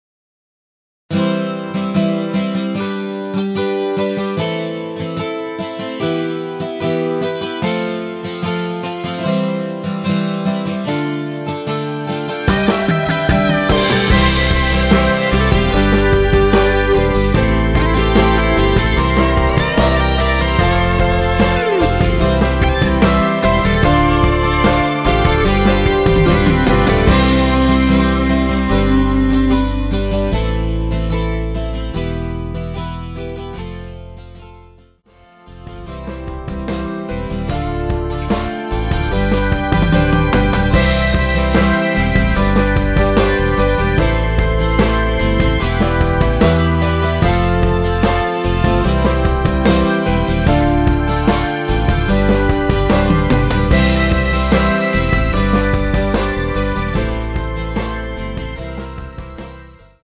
원키에서(+5)올린 MR입니다.(미리듣기 참조)
앞부분30초, 뒷부분30초씩 편집해서 올려 드리고 있습니다.